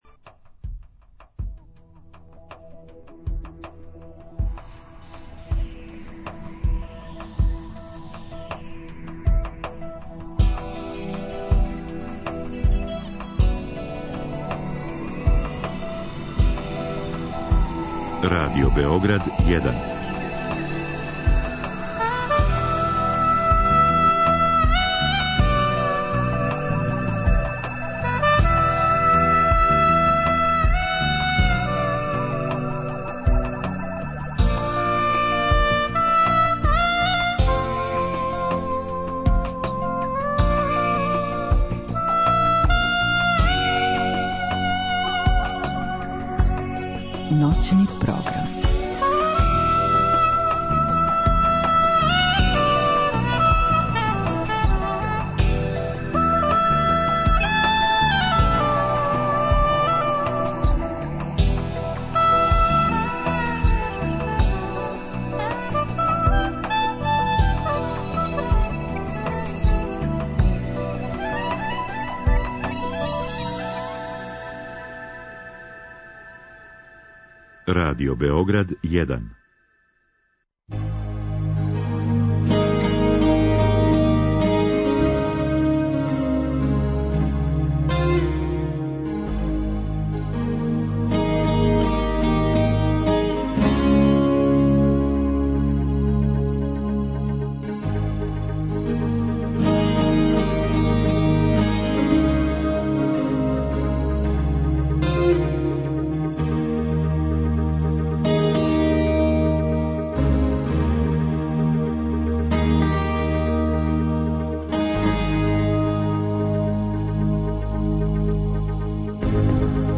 У трећем и четвртом сату емисије чућемо разговоре, снимљене у Смедереву, са водитељима радионица и учесницима пројекта АРТY.